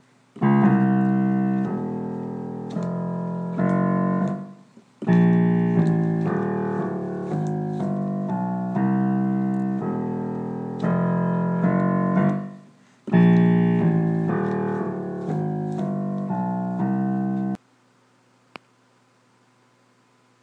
Jazzy piano bass line boo